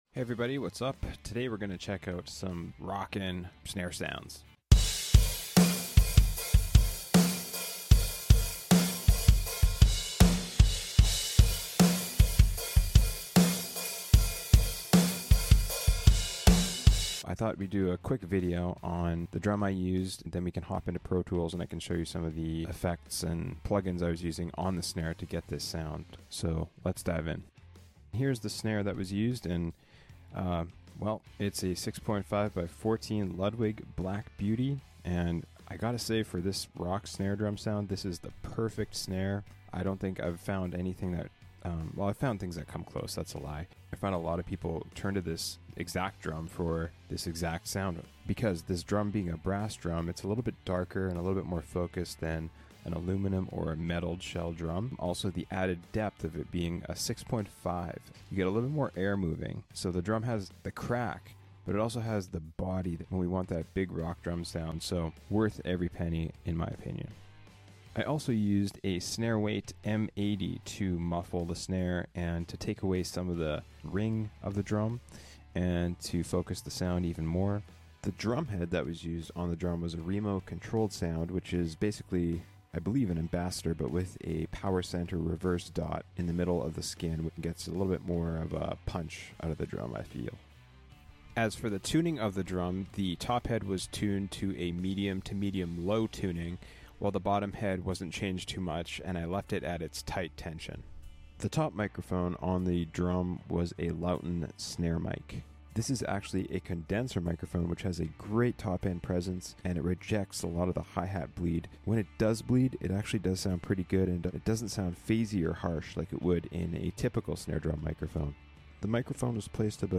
Here are some ideas and techniques on how to get a huge sounding rock snare drum tone. I outline the drum that used for this sound, the mics and some mixing techniques.